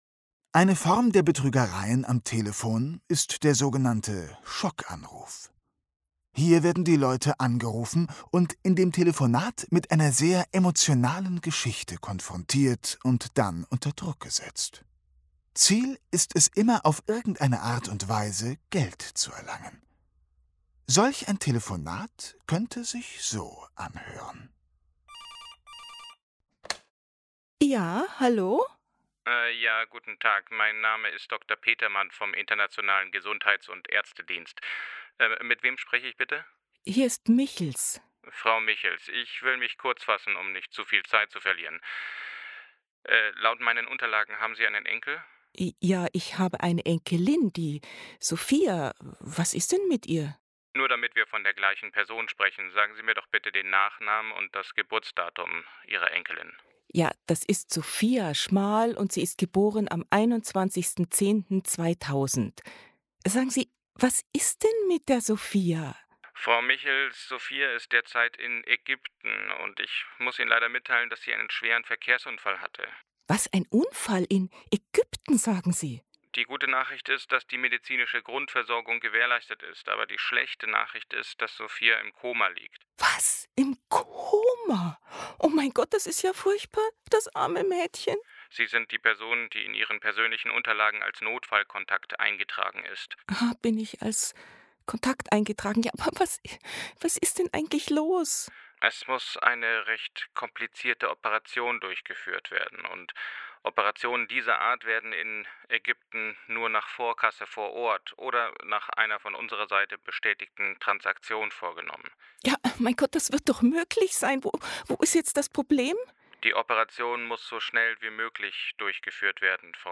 Es handelt sich hier nicht um ein Hörspiel im klassischen Sinn.